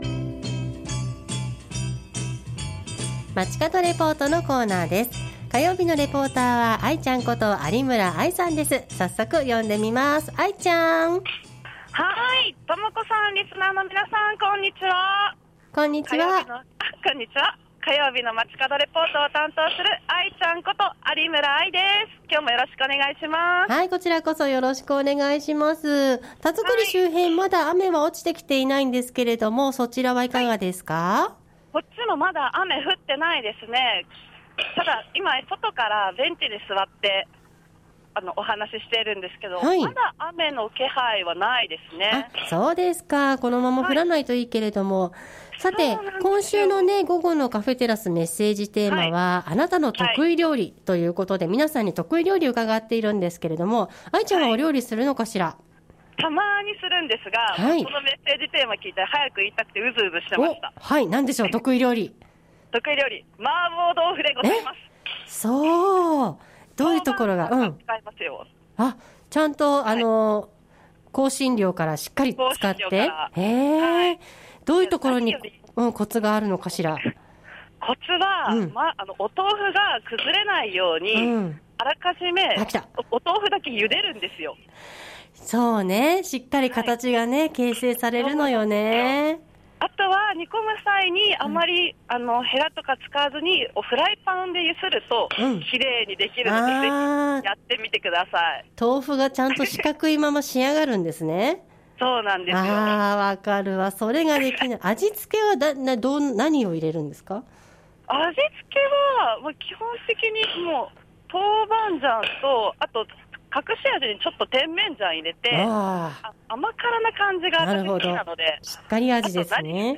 本日は柴崎1丁目にある、甲州街道沿いにある調布市唯一のバッティングセンター、「柴崎バッティングセンター」からお届けしました。
中継時もこのベンチに座り、野川をバックに皆さんがバッティングする風景を眺めながら放送しました！